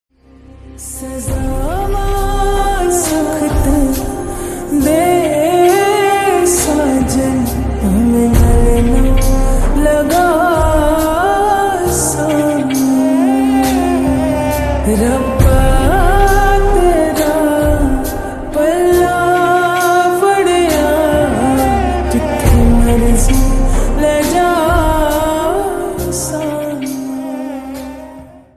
Punjabi sang
(Slowed + Reverb)